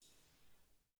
Metal_14.wav